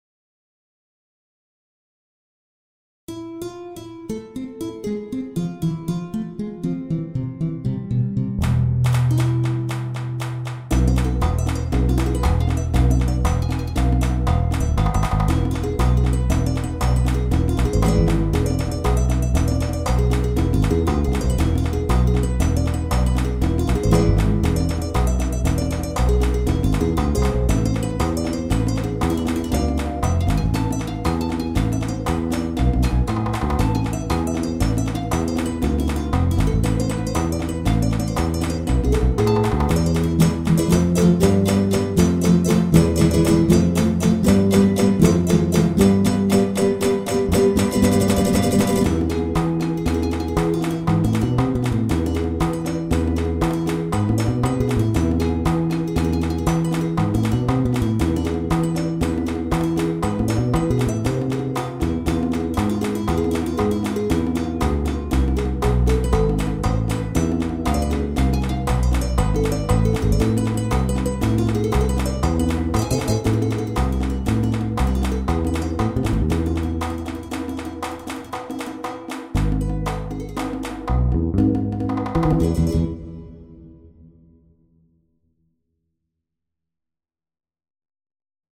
BGM
カントリーショート